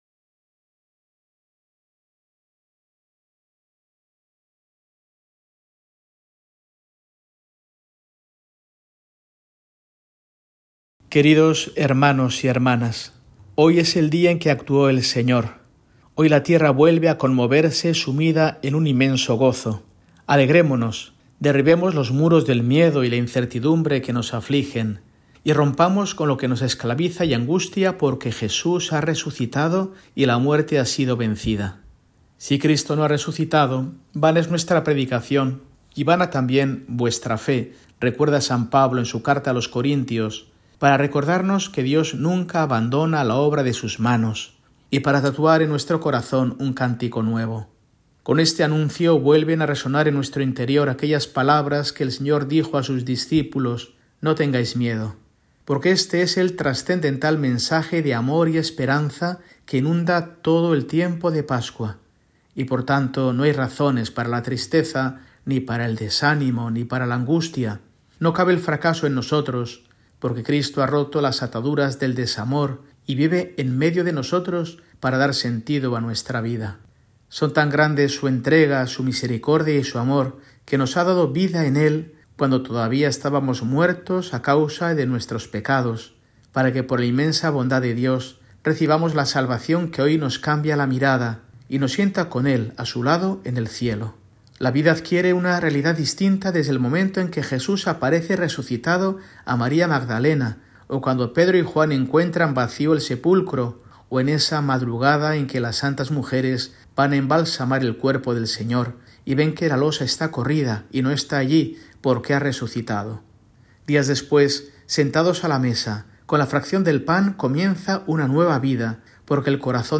Mensaje semanal de Mons. Mario Iceta Gavicagogeascoa, arzobispo de Burgos, para el domingo, 20 de abril de 2025, Domingo de Resurrección